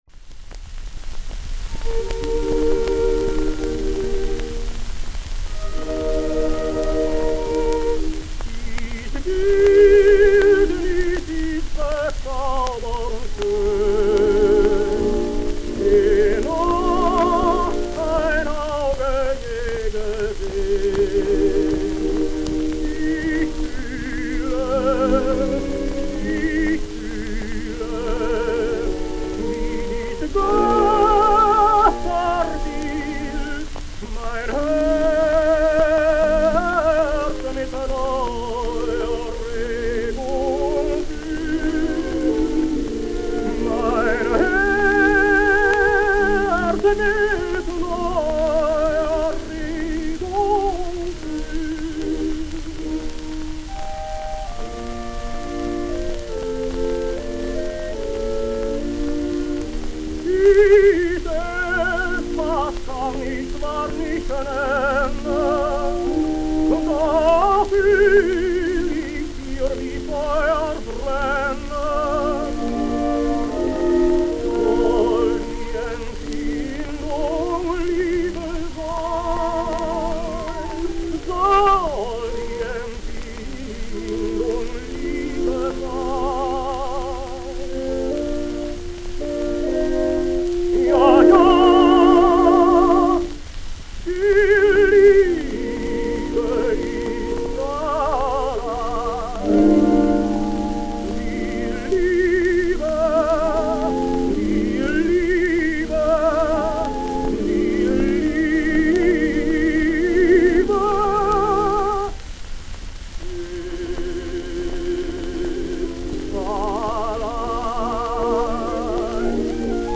From 1914 to 1933, he was first lyrical tenor in Leipzig; already from 1923, and through 1954, he taught voice at the Leipzig Conservatory.
Parlophon, Berlin, 12 September 1922